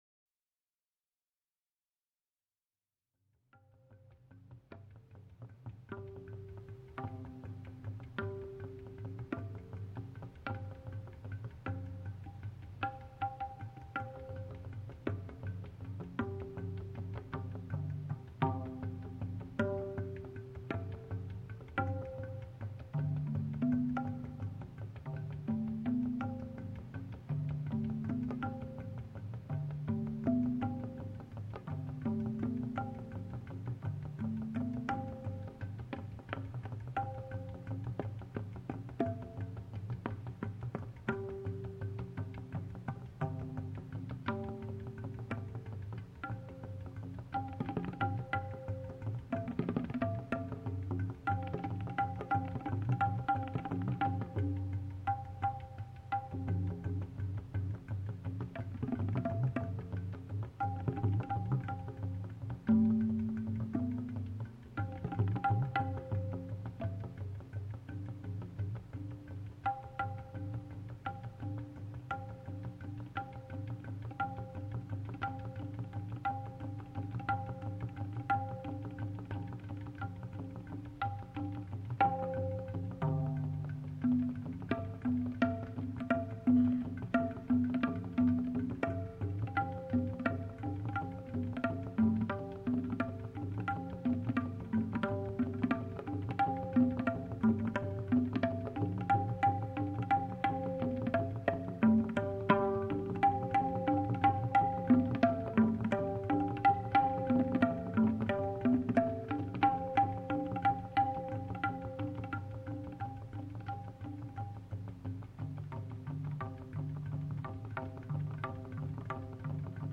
TABLA TARANG
World Music